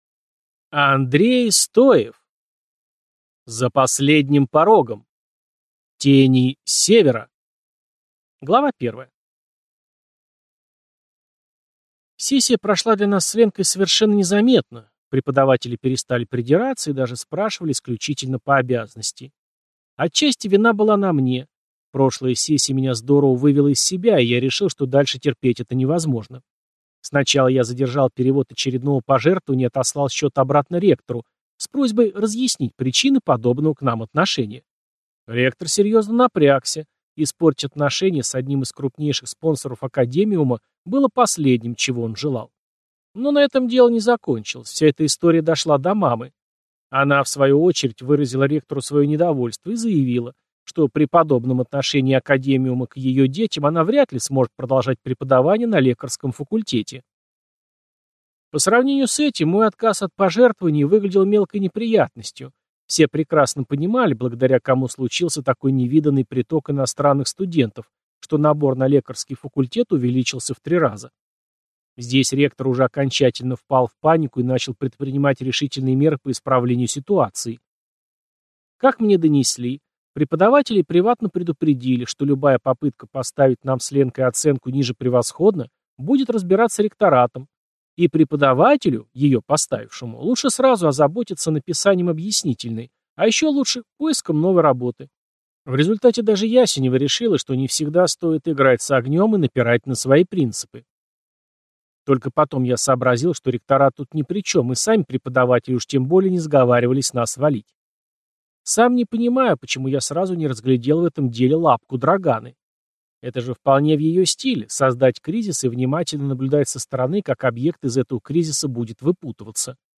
За последним порогом. Тени Севера. Аудиокнига - Стоев – Попаданец в Другой Мир